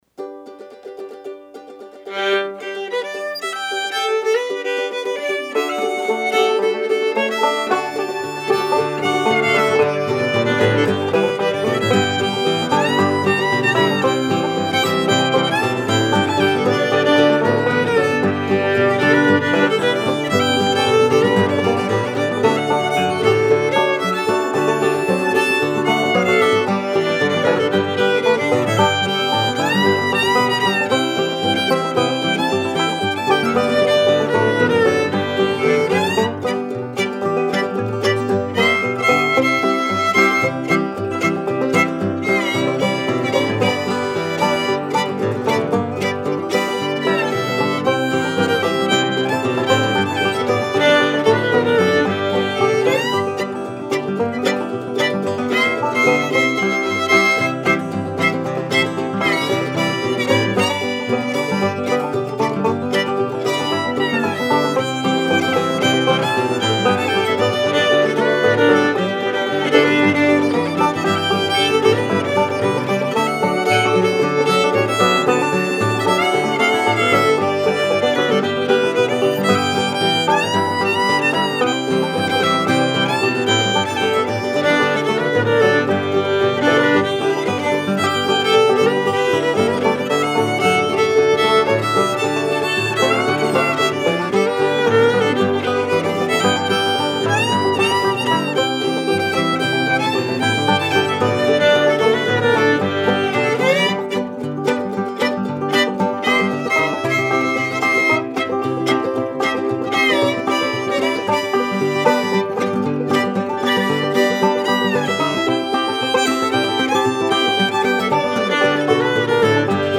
ukulele
guitar
banjo
fiddle